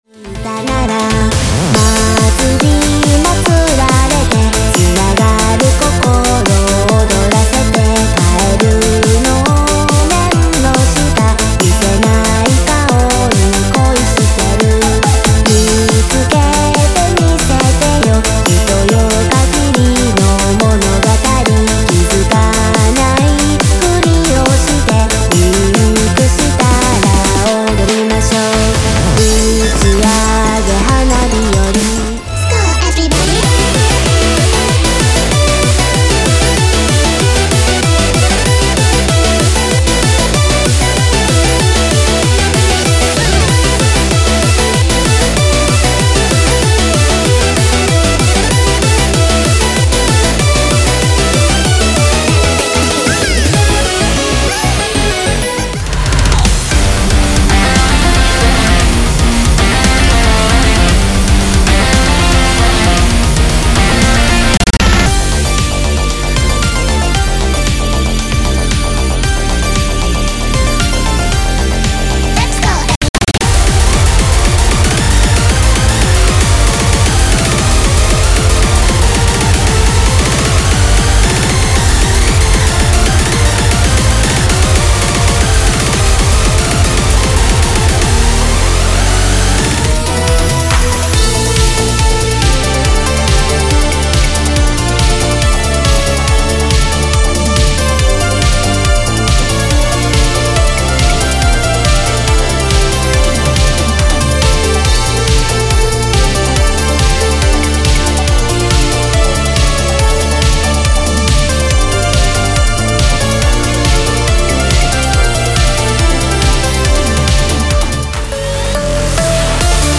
疾走するリズム、幻想のビート?
SYNTHWAVEやHARDCORE等の幅広いジャンルの楽曲を詰め込みました。
Genre：BUBBLEGUM DANCE / BPM：140
Genre：ハッピー疾走コア / BPM：191
Genre：Ultimate Speedcore / BPM：230
Genre：SYNTHWAVE / BPM：140
Genre：PIANO TRANCE / BPM：140
Genre：HANDS UP / BPM：149
Genre：HARD DANCE / BPM：155
Genre：DUBSTEP / BPM：150
Genre：EUROBEAT / BPM：165
Genre：NOSTALGIC WALTZCORE / BPM：196
Genre：FUNKOT / BPM：180
Genre：SYMPHONIC HARDCORE / BPM：200
Genre：HARD RENAISSANCE / BPM：199